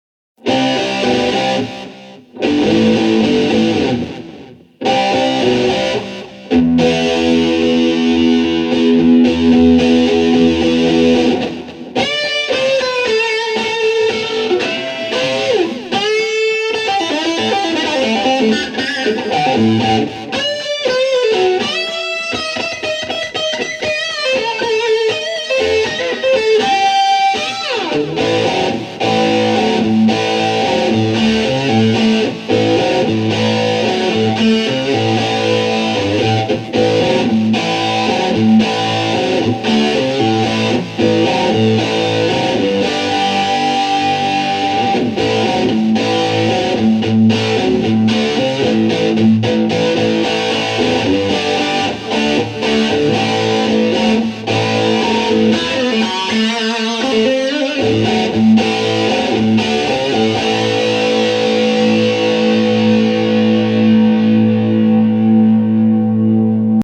puis j'ajoute un Exciter pour relever un peu le "krisss" du son; à la fin je met un Limiter pour empêcher que ça clip et puis voilà le travail....; écoutez le résultat qui est assez impressionnant :